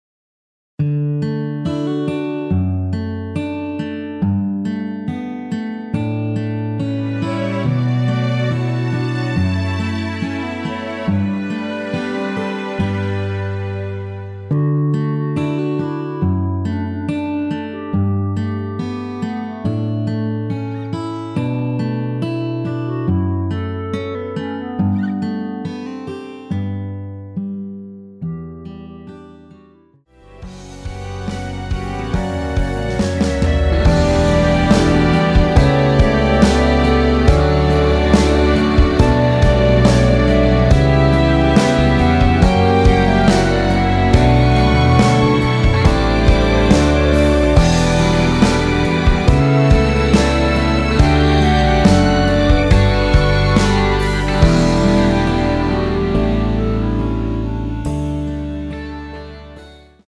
원키 멜로디 포함된 MR 입니다.(미리듣기 참조)
D
앞부분30초, 뒷부분30초씩 편집해서 올려 드리고 있습니다.